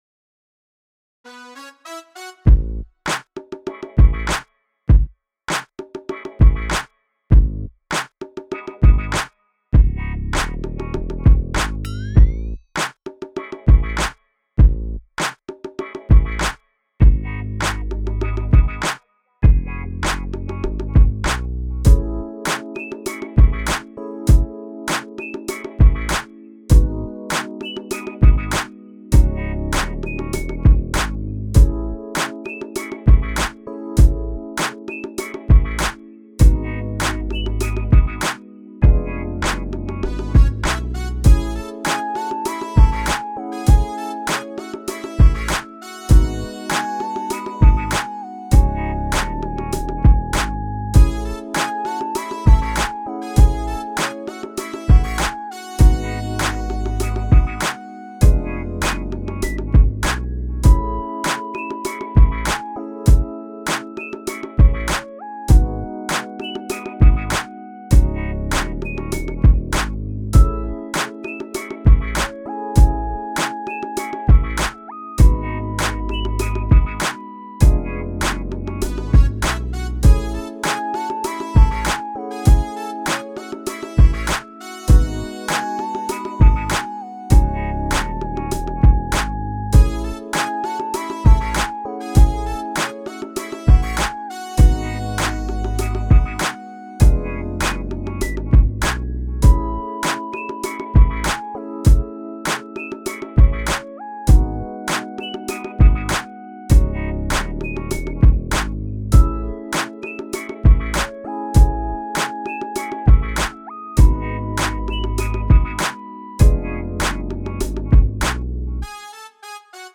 Westside Love (instrumental) | 리드머 - 대한민국 힙합/알앤비 미디어
하지만 브라스 섹션이 너무 vsti색이 진하게 나서 기계적인 느낌이 납니다.